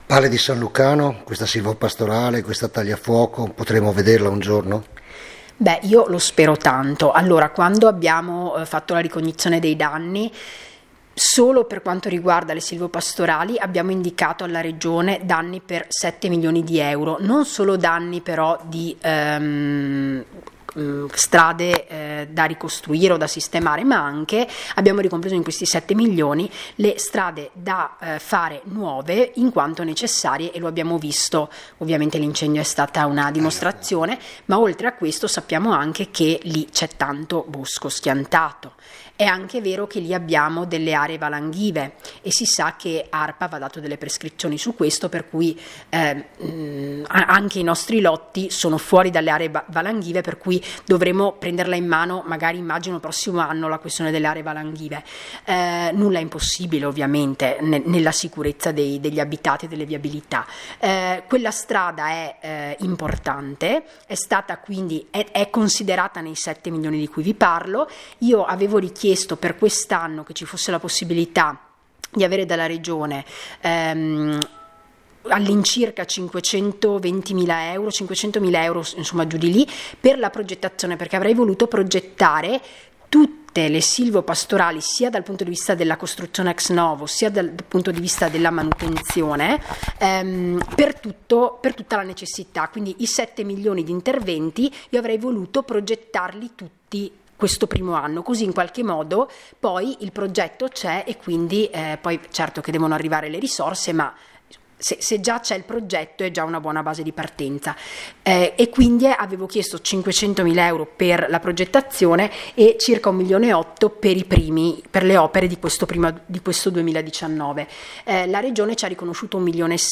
GIORNALE RADIOPIU 11 GIUGNO 2019